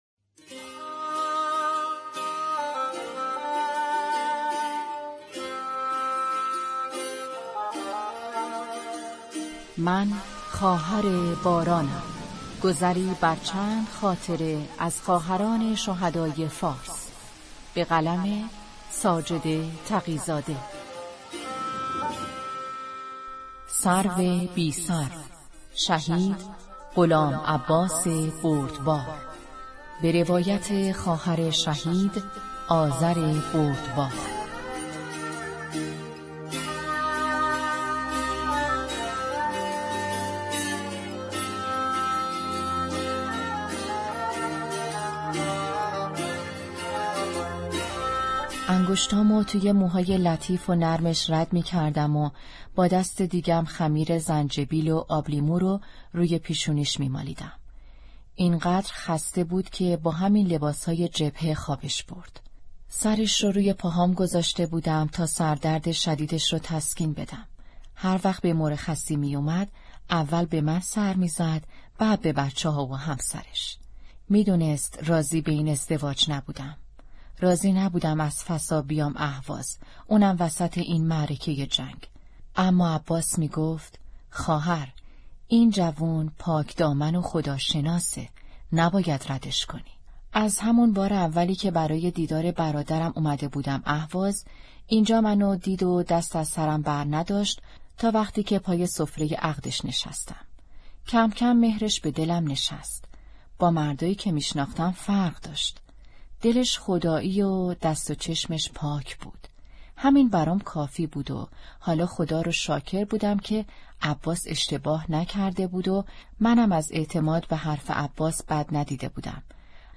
کتاب صوتی «من خواهر بارانم» بخش دهم